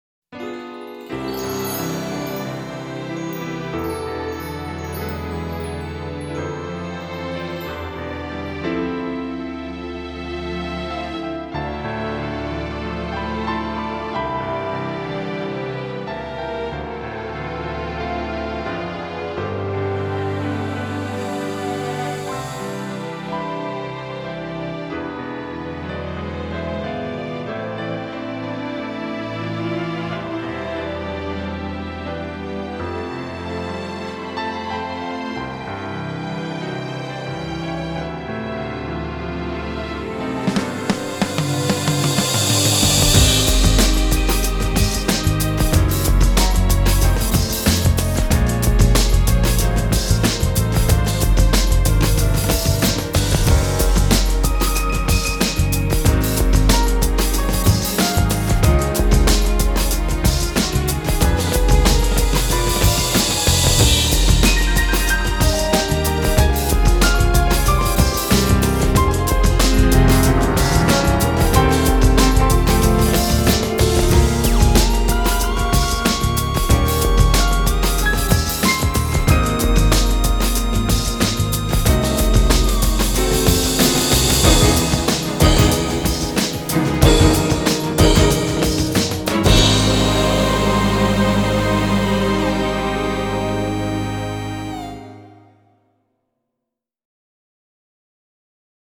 BPM70-93
Audio QualityPerfect (High Quality)
Genre: AMBIENT.